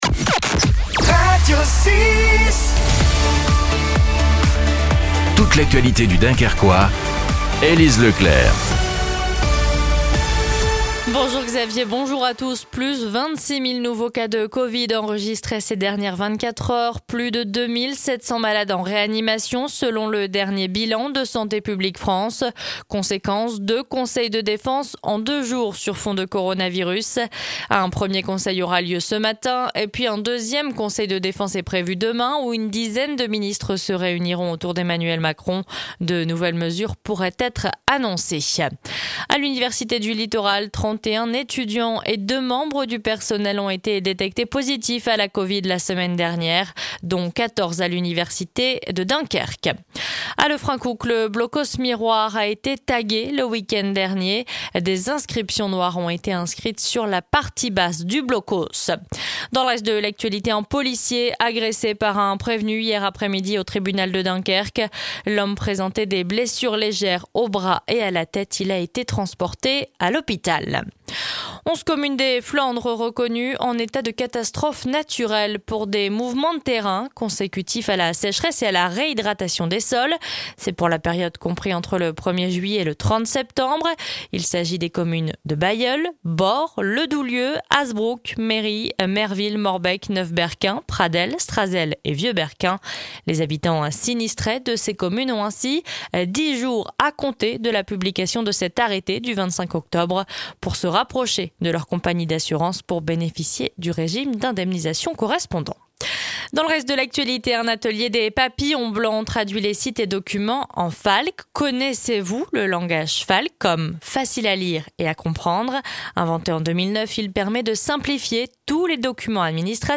Le journal du mardi 27 octobre dans le Dunkerquois